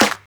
Snares
pcp_snare09.wav